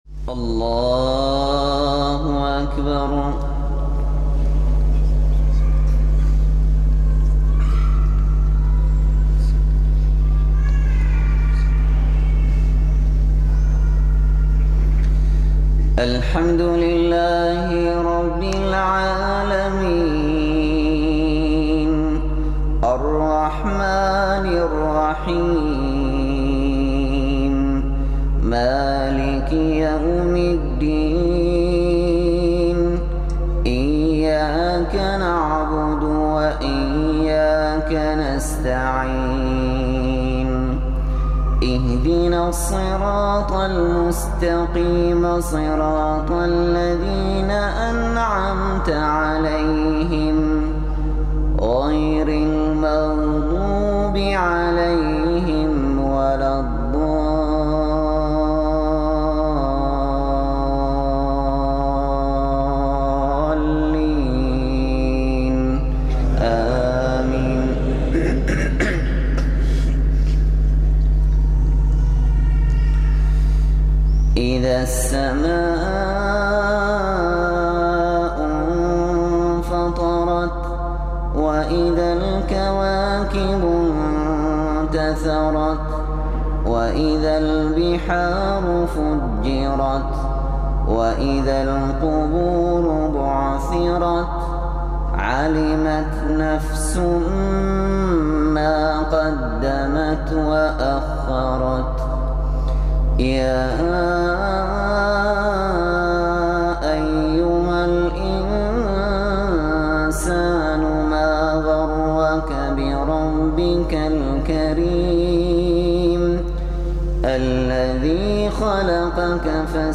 Şeyh Şatiri’den Sultanahmet’te Akşam Namazı